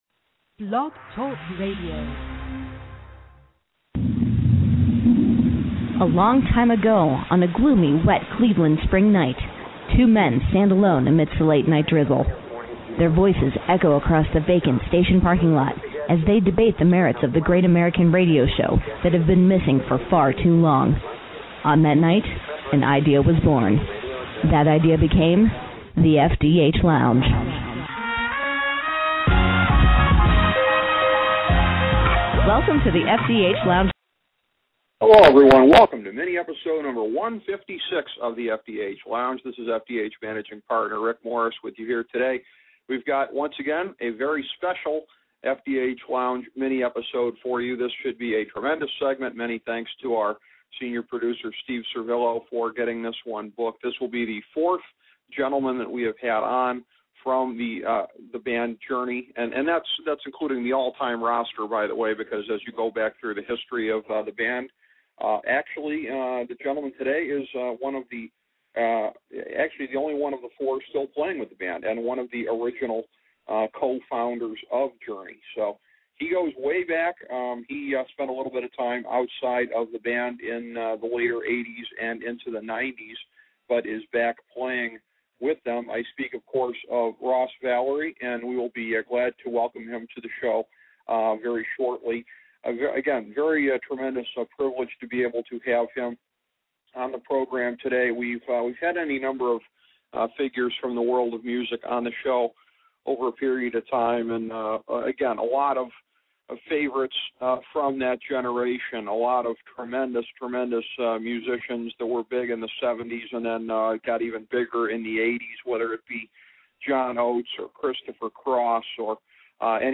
A conversation with Ross Valory